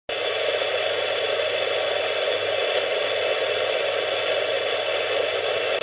10 MHz AM sur Kenwood TS-50 (sortie Phones)
On entend très nettement l'onduleur.